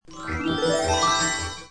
Harp Build